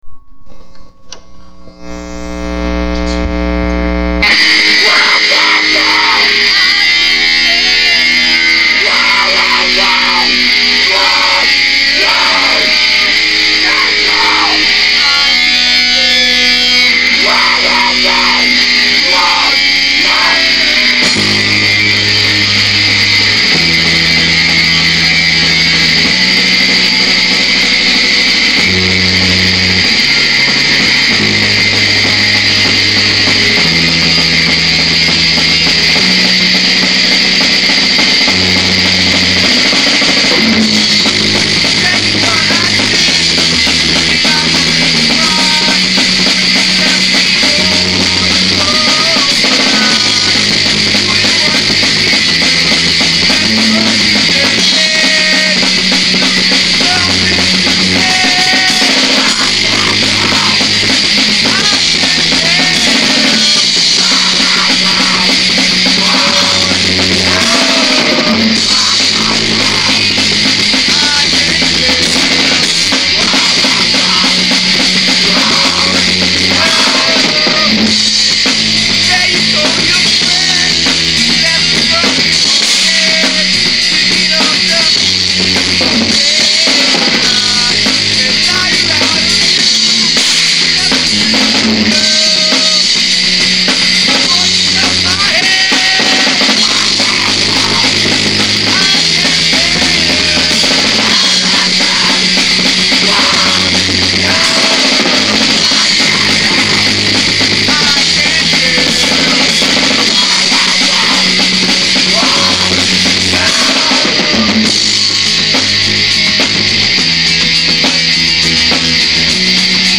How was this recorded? crappy sound quality but still.